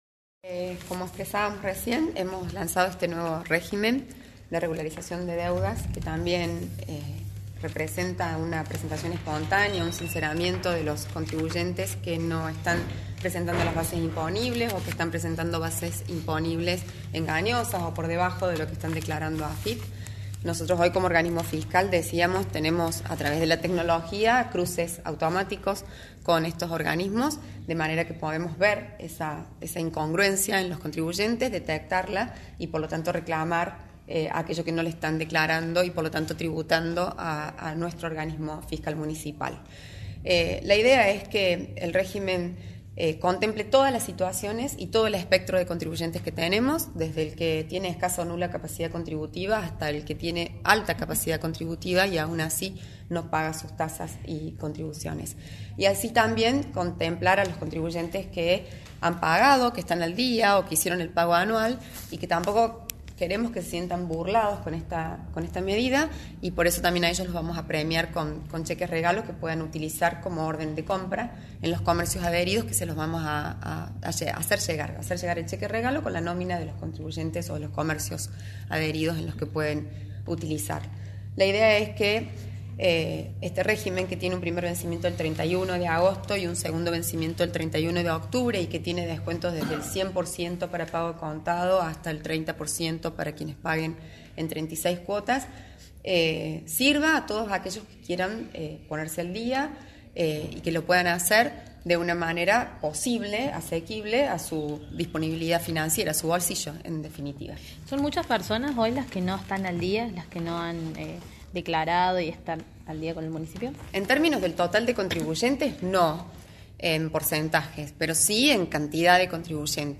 AUDIO – DANIELA LUCARELLI, SEC. DE FINANZAS MUNIC. VILLA MARÍA
Dialogamos con Daniela Lucarelli, Secretaria de Economía y Finanzas de la Municipalidad de Villa María, quien nos brinda detalles específicos sobr elos beneficios para los pagos de tasas y servicios.
Daniela-Lucarelli-secretaria-de-economia-y-finanzas-de-la-municipalidad-.mp3